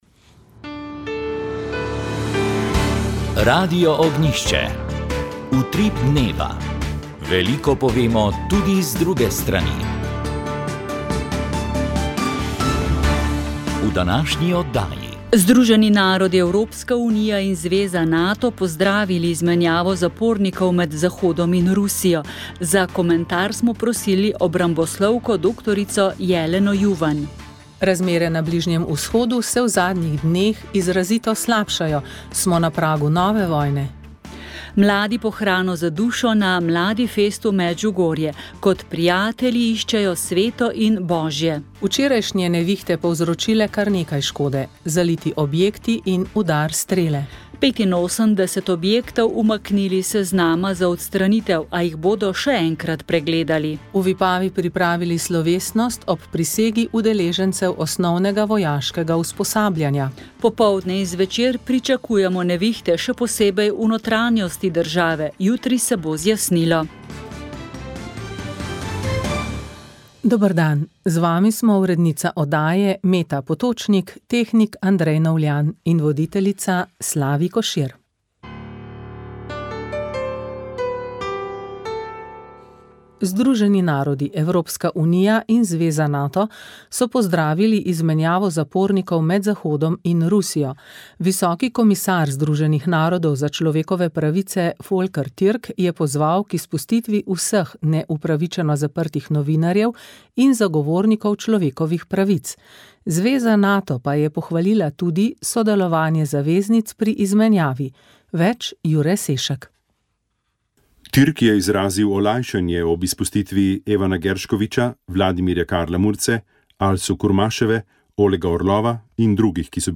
Informativne oddaje